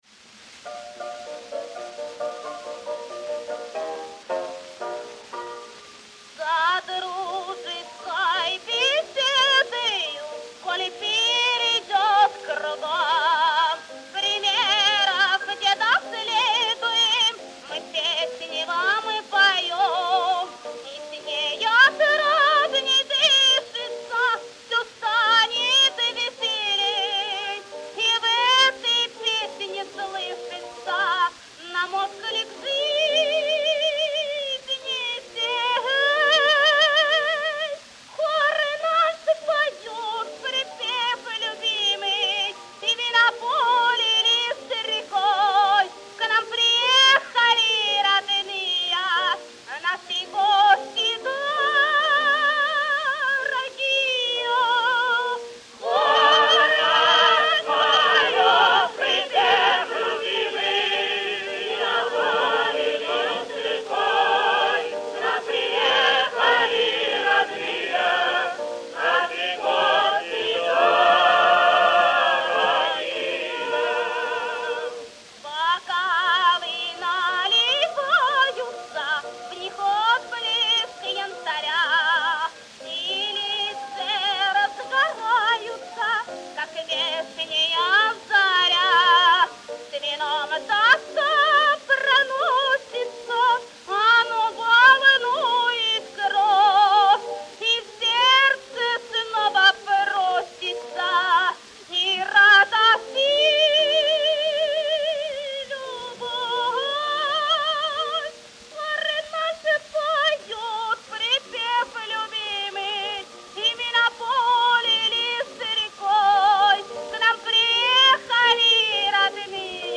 Исп. хоръ ''Россия'' подъ упр. Глогау и Садовникова -  За дружеской беседой